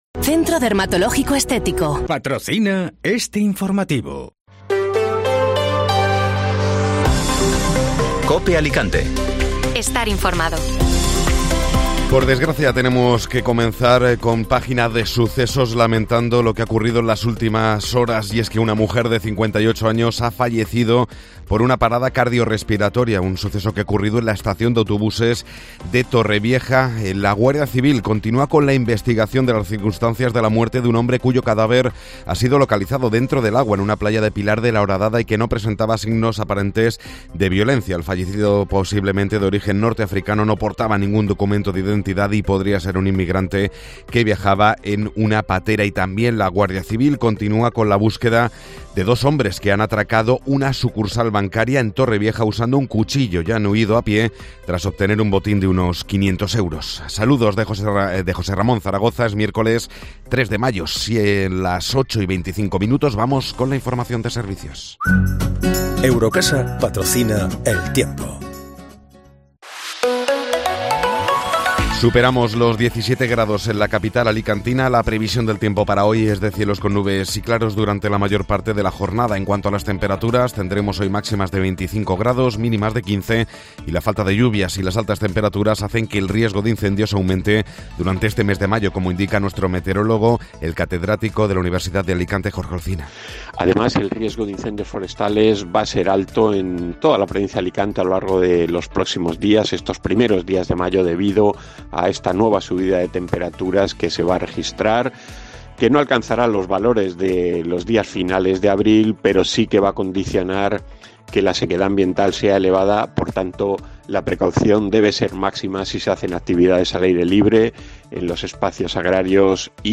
Informativo Matinal (Miércoles 3 de Mayo)